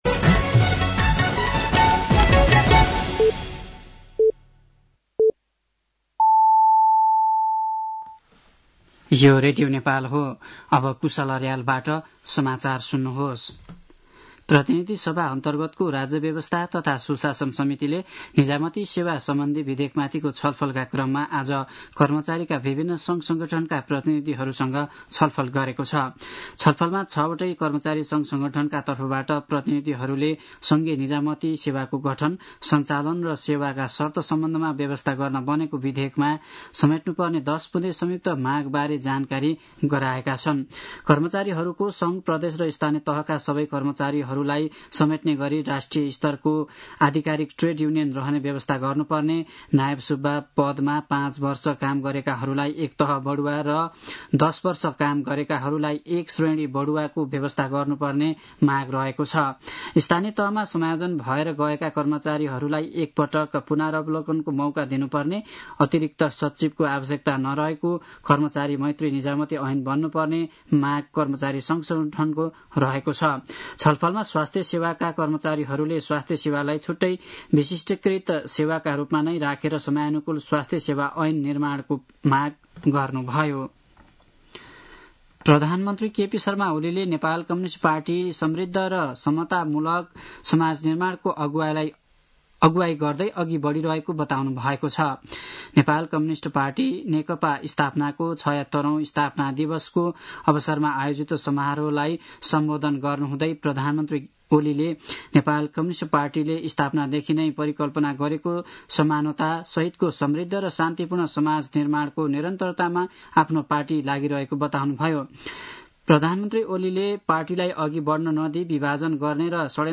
साँझ ५ बजेको नेपाली समाचार : ९ वैशाख , २०८२
5-pm-news-3.mp3